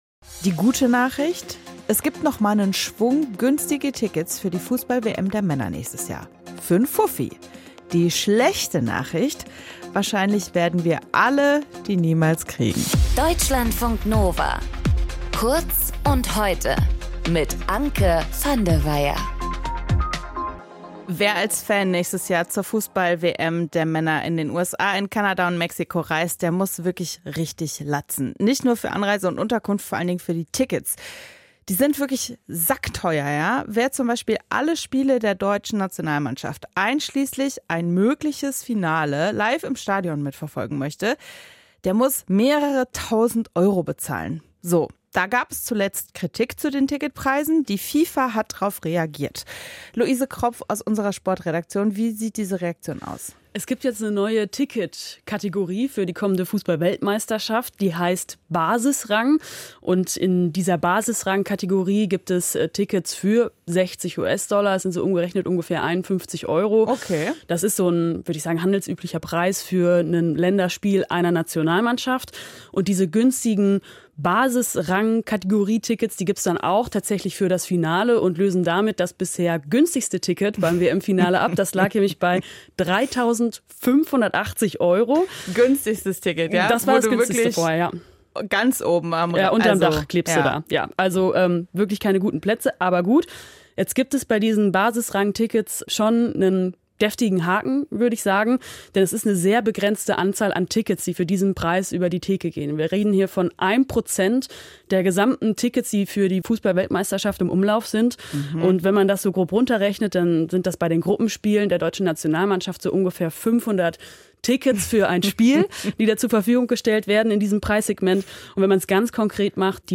In dieser Folge mit:
Moderation:
Gesprächspartnerin: